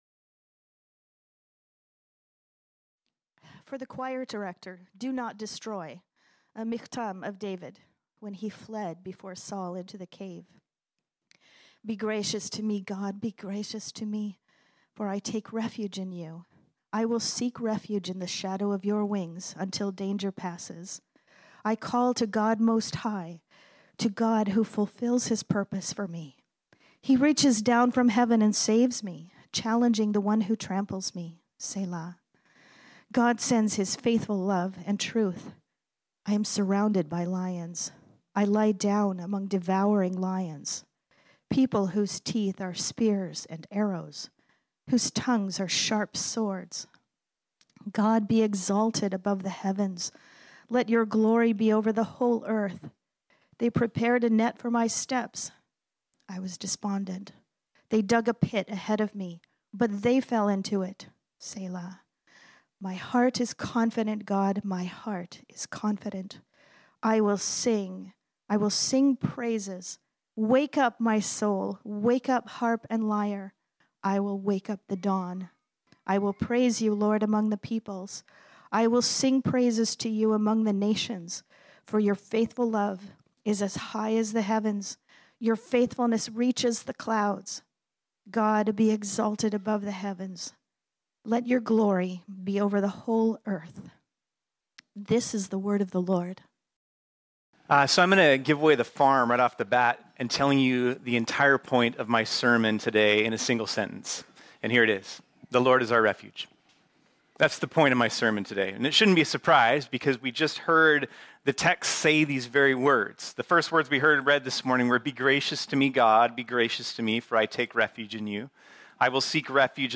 This sermon was originally preached on Sunday, June 25, 2023.